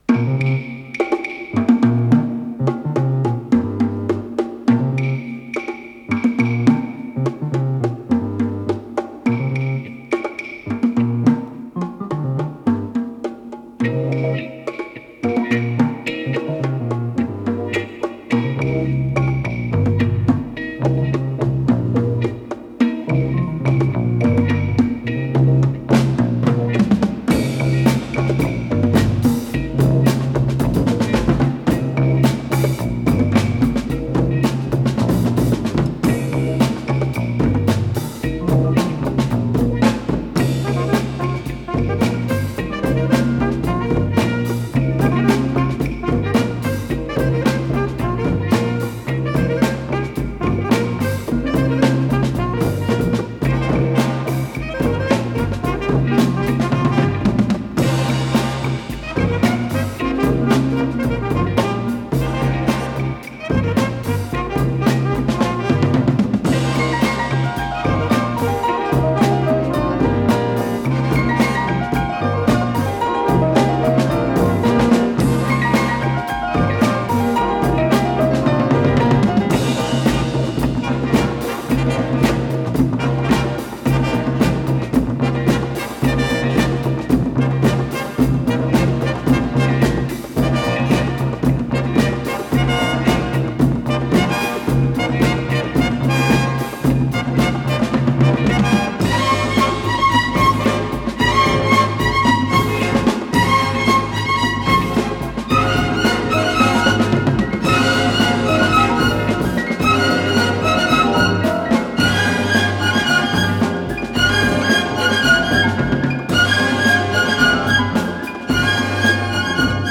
с профессиональной магнитной ленты